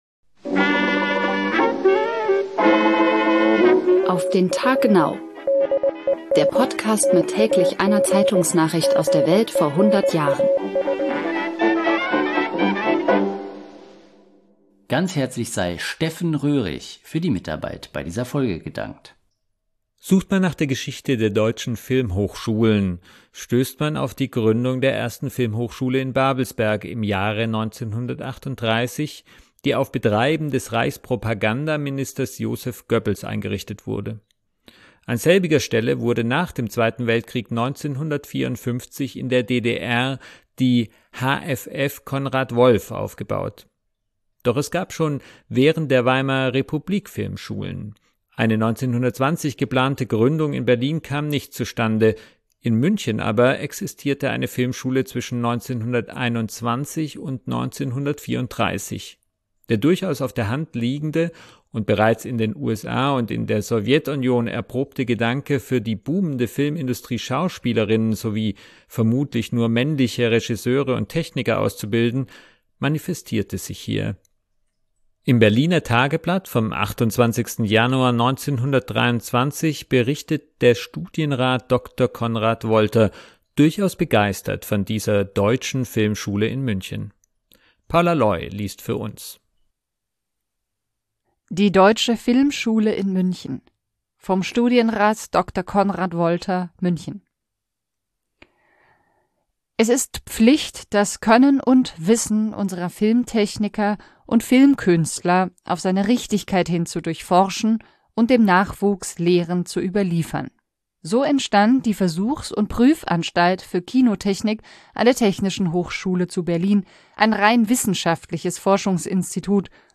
liest für uns.